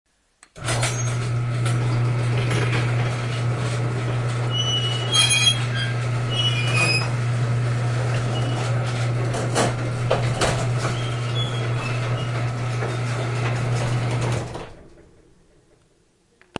Squeaky Garage Door Open
squeaky-garage-door-open-31164.mp3